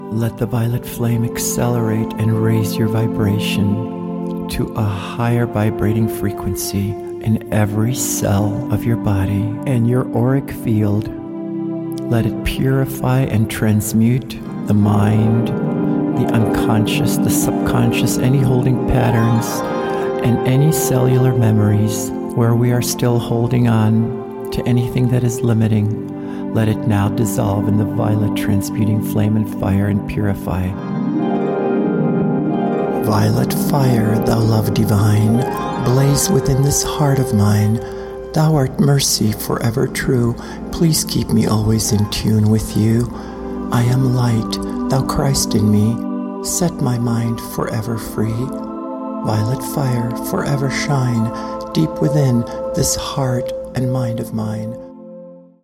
A dynamic guided Meditation to Empower Your Life.
With specially designed 432 Hz Solfeggio Frequencies to reactivate and heal our DNA for optimal health.
1.-ARCH.MORN-MEDITATION.mp3